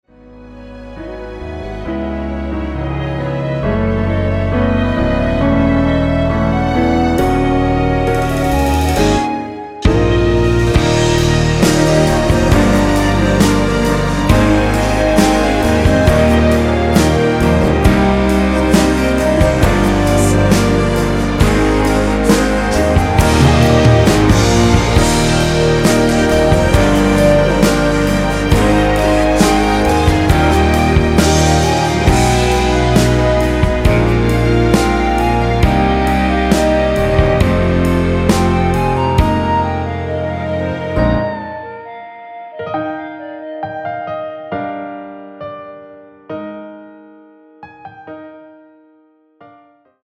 이곡의 코러스는 미리듣기에 나오는 부분밖에 없으니 참고 하시면 되겠습니다.
원키에서(-1)내린 멜로디와 코러스 포함된 MR입니다.(미리듣기 확인)
Db
앞부분30초, 뒷부분30초씩 편집해서 올려 드리고 있습니다.